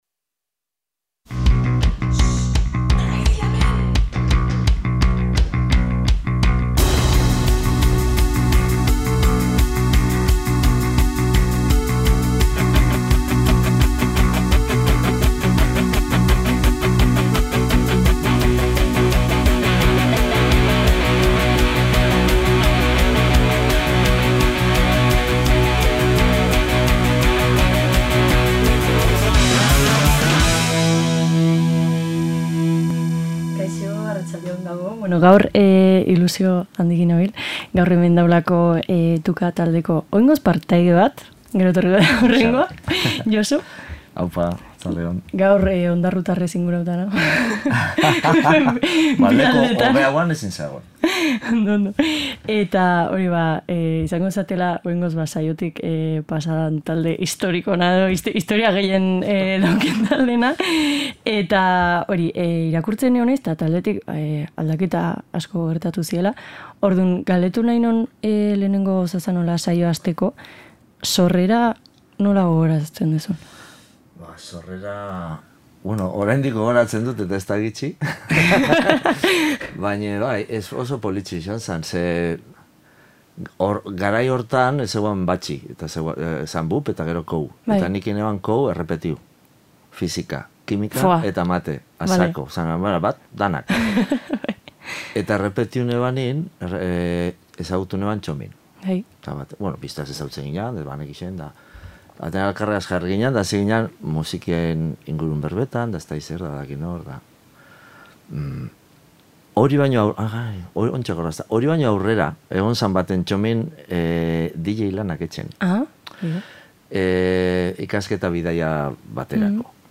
Tu-K taldea izan dugu gurekin, distantzian batuta. Belaunaldi arteko loturekin batera, hainbat gai irten dira mahai gainera eta solasaldiak kanten zerrenda eraldatu du. 80. eta 90. hamarkadetatik gaur arte antzemandakoak aipatu ditugu, bizitzak ekarri dituen bueltekin batera.